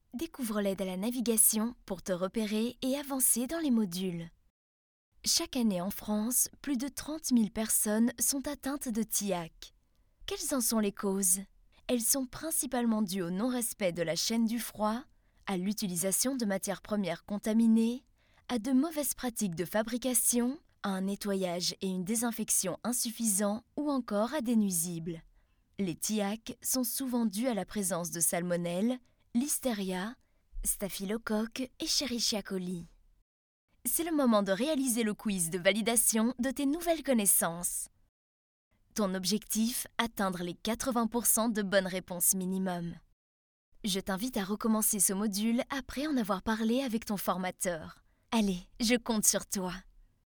Jong, Natuurlijk, Speels, Stedelijk, Stoer
E-learning
Ik heb mijn eigen thuisstudio en lever steeds extreem snel.